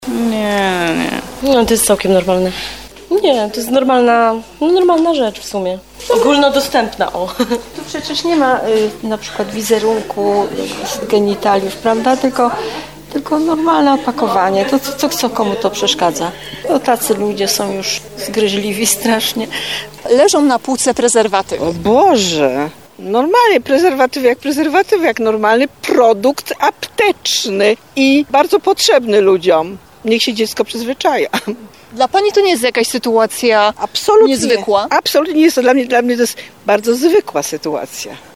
Osoby zapytane przez nas w aptece oraz w jej okolicach twierdzą, że to sytuacja zupełnie normalna.